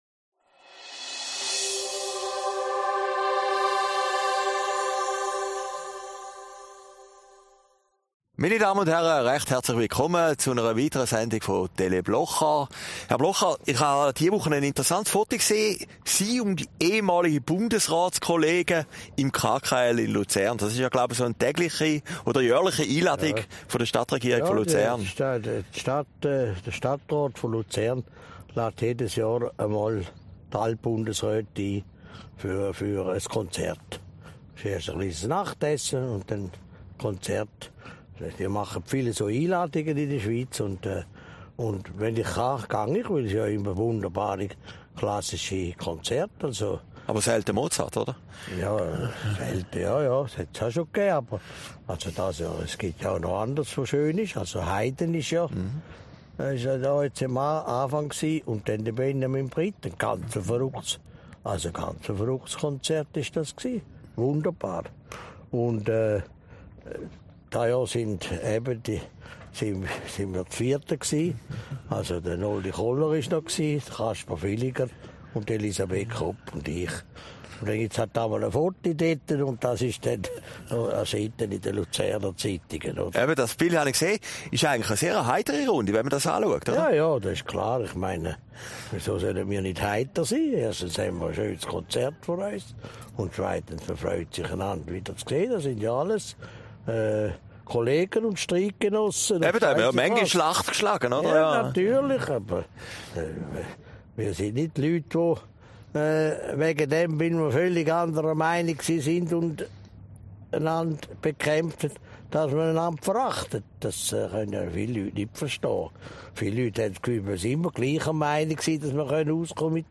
Aufgezeichnet in Herrliberg, 20 September 2019